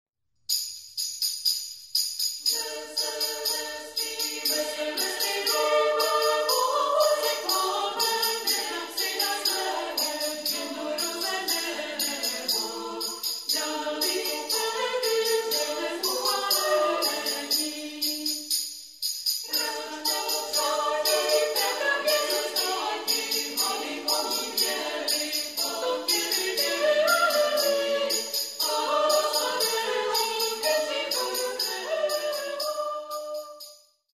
Komorní pěvecké sdružení Ambrosius
Demo nahrávka, prosinec 2000